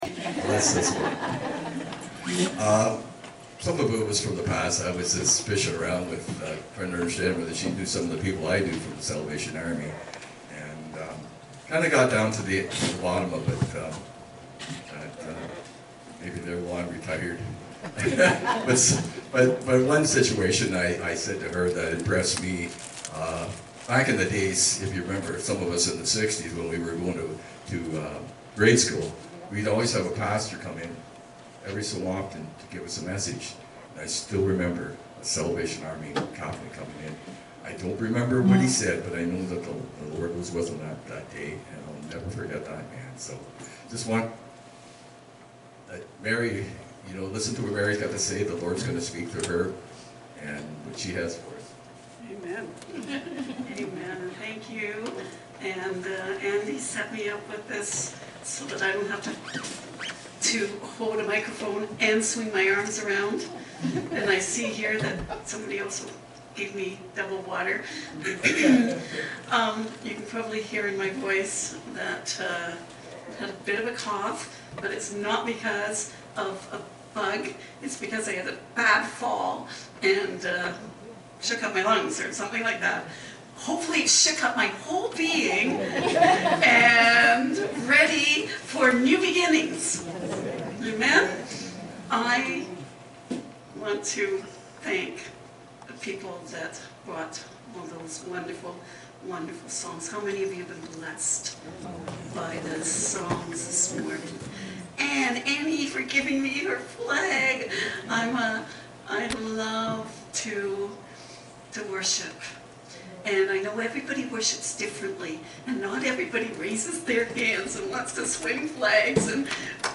Sermon January 12